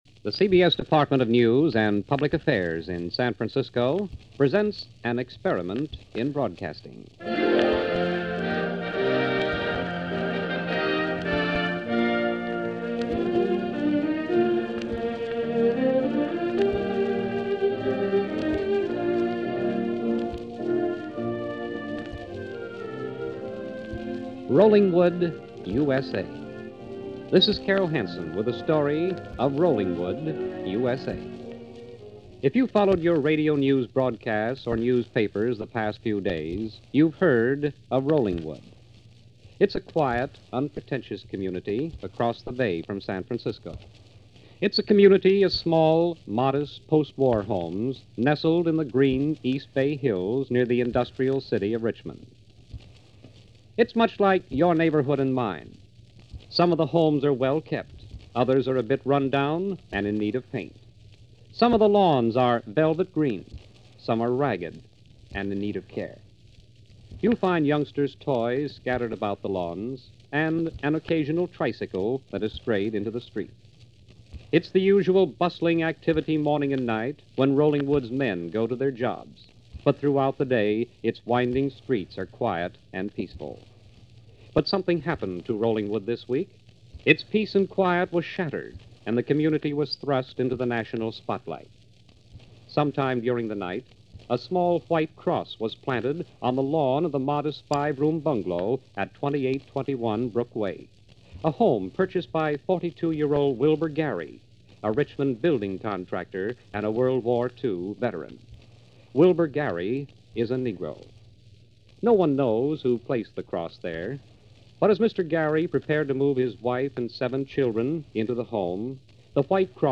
The Great Radio Documentaries